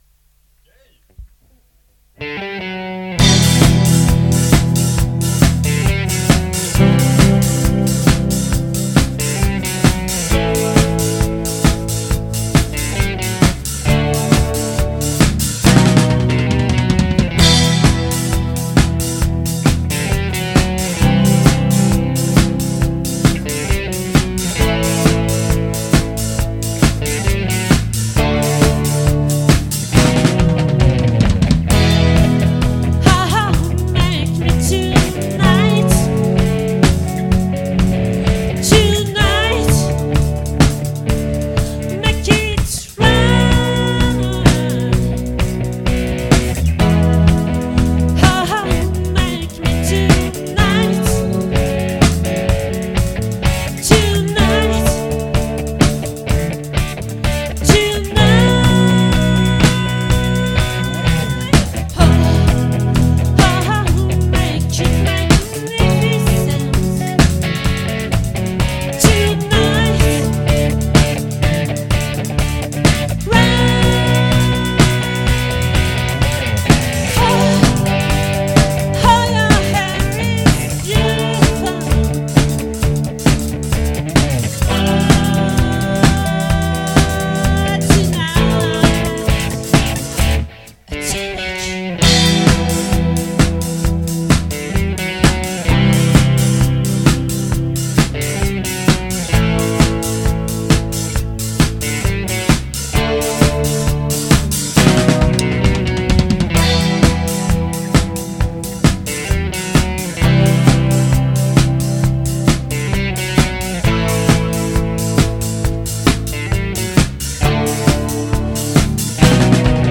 🏠 Accueil Repetitions Records_2023_03_29_OLVRE